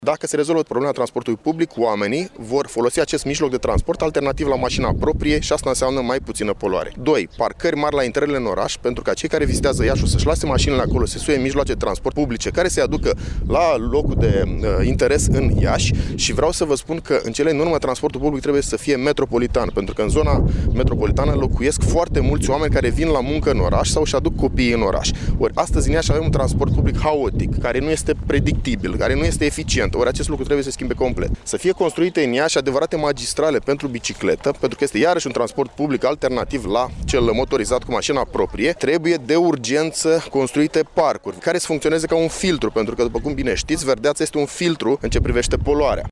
Consilierii locali şi judeţeni ai PNL au protestat, astăzi, în faţa Primăriei Iaşi.
Preşedintele organizaţiei municipale Iaşi a PNL, deputatul Marius Bodea, a mai subliniat că o soluţie este dezvoltarea transportului public, cu prepondenrenţă a celui electric.